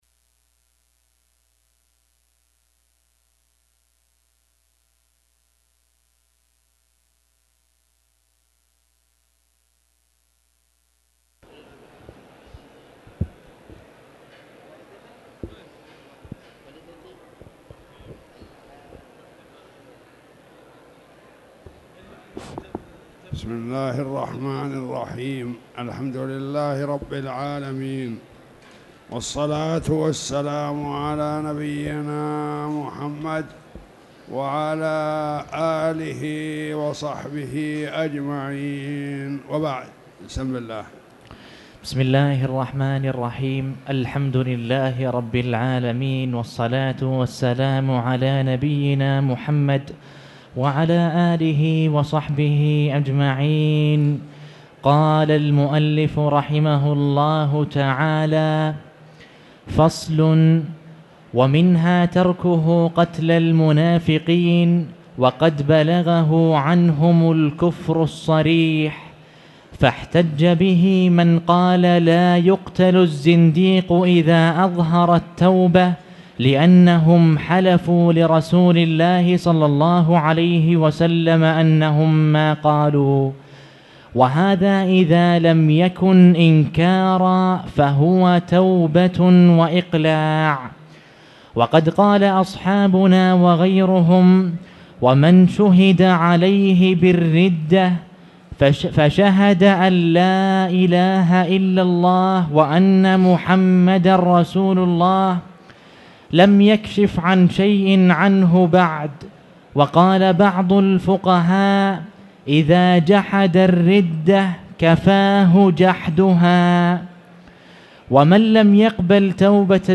تاريخ النشر ١٧ ربيع الأول ١٤٣٨ هـ المكان: المسجد الحرام الشيخ